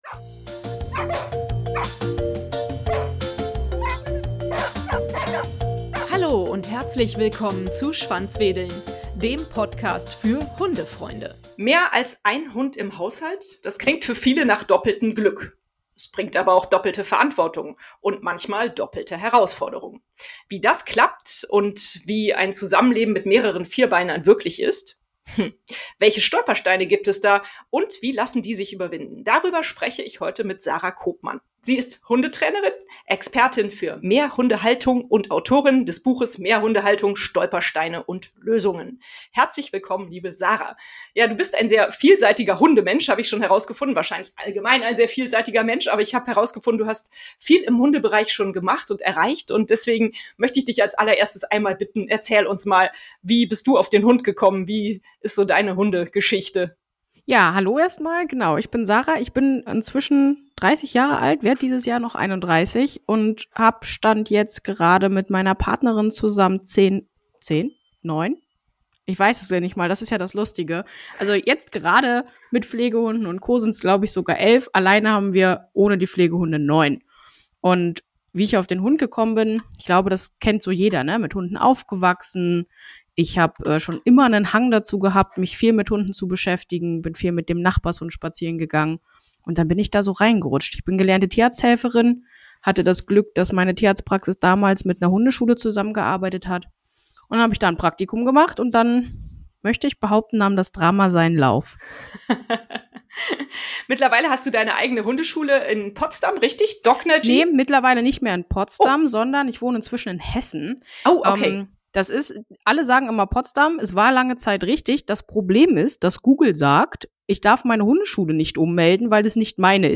Herzlich willkommen im Interview